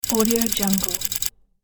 دانلود افکت صدای ماشین تایپ
صدای دلنشین تایپ، برای پروژه‌های نوستالژیک و کلاسیک شما
صدای ماشین تایپ با آن ریتم منظم و صدای ضربه کلیدها، می‌تواند به پروژه‌های شما روح تازه‌ای ببخشد و مخاطب را به دنیای گذشته ببرد.
• افزایش تمرکز و آرامش: صدای منظم و ریتمیک ماشین تایپ می‌تواند به عنوان یک پس‌زمینه آرامش‌بخش استفاده شود و به افزایش تمرکز کمک کند.
16-Bit Stereo, 44.1 kHz